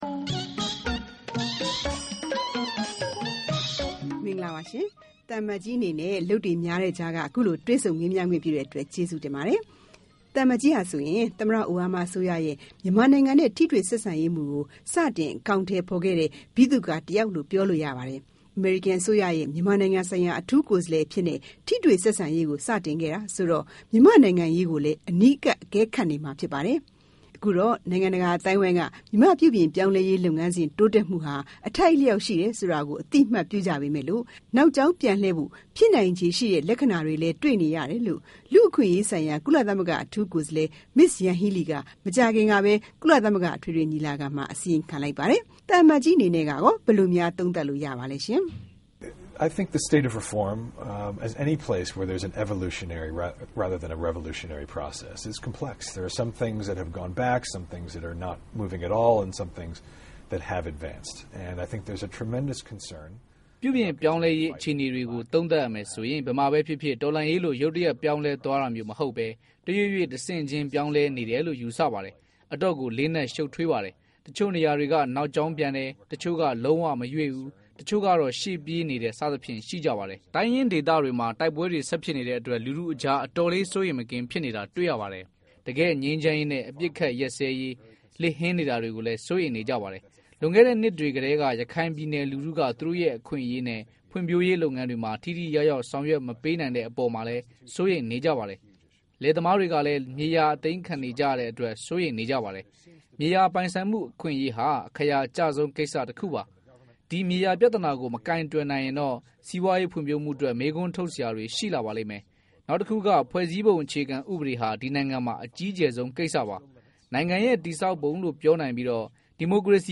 သံအမတ်ကြီး Derek Mitchell နဲ့ တွေ့ဆုံမေးမြန်း